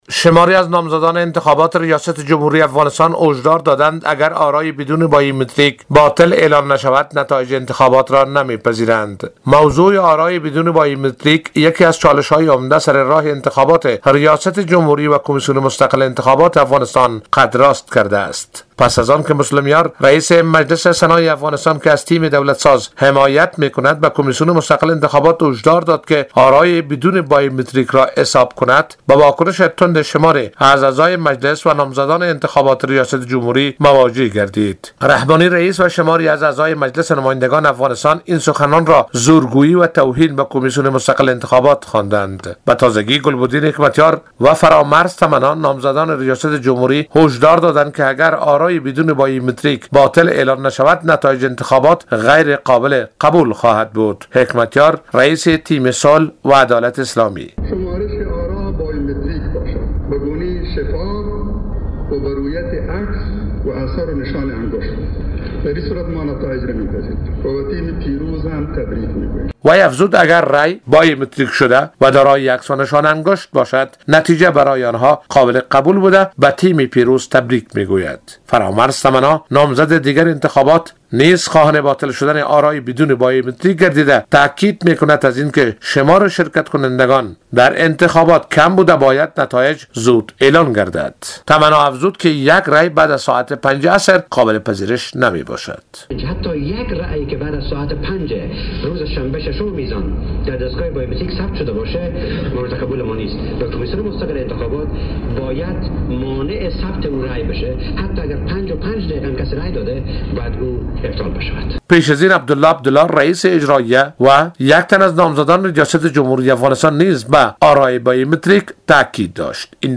جزئیات بیشتر در گزارش خبرنگار رادیودری: